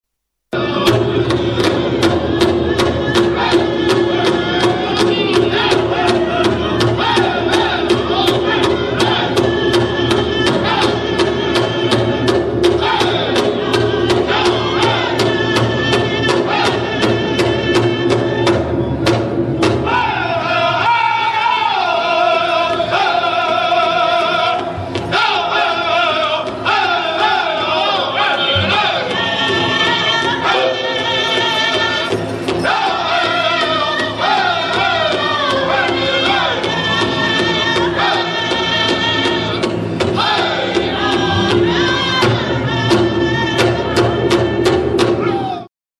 Pow Wow Albuquerque 2008
Extraits sonores enregistrés en direct sur place
Chant et tambour
8 à 10 indiens sont assis en rond autour d'un tambour qu'ils frappent en cadence tout en chantant.